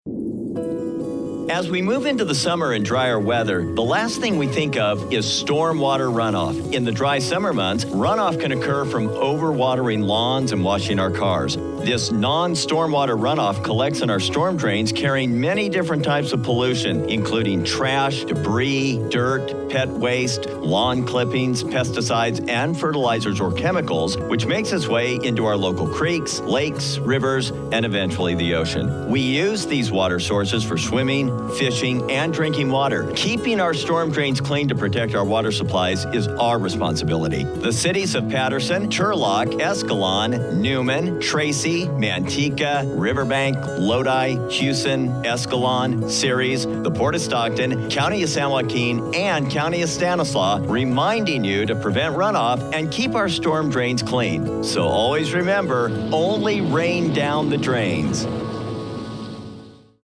Stormwater Radio Ads - Outreach & Education Campaigns Public Works Stormwater Partnership 2021 Stormwater Partnership Mixdown 2018 City of Patterson Ad